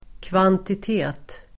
Uttal: [kvantit'e:t]